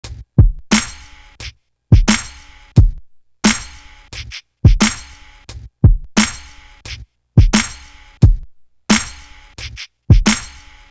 Dub Drum.wav